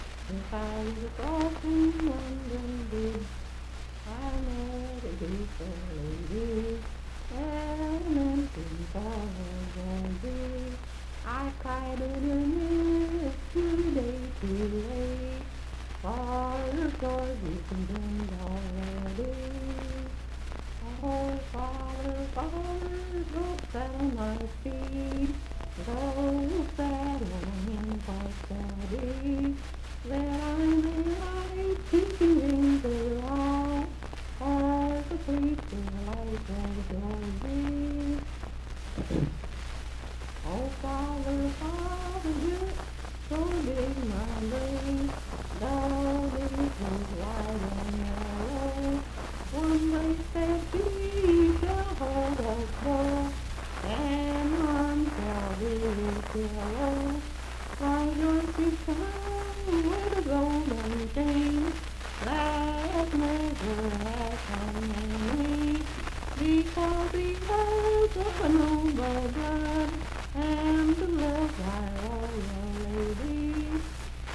Unaccompanied vocal music
Voice (sung)
Moorefield (W. Va.), Hardy County (W. Va.)